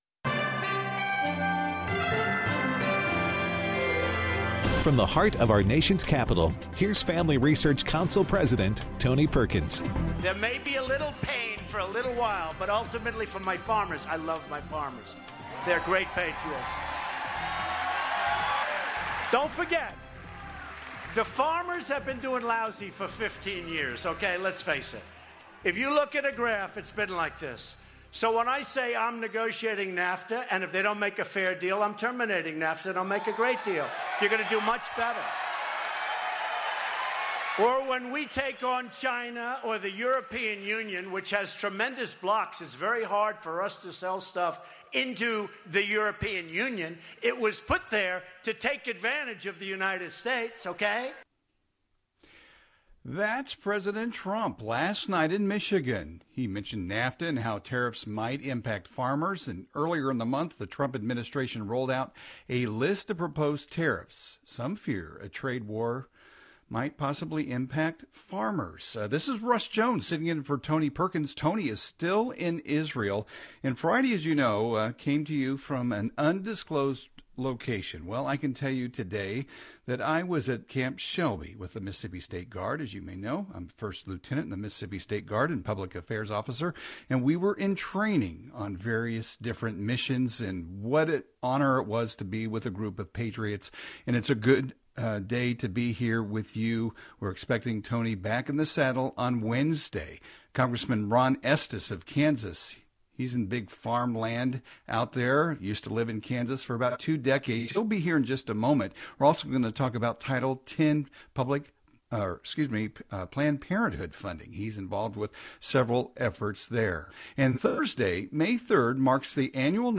Rep. Ron Estes (R-Kan.), joins our guest host to discuss the impacts of tariffs on farmers and the Tax Cuts and Jobs Act on economic growth.